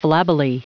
Prononciation du mot flabbily en anglais (fichier audio)
Prononciation du mot : flabbily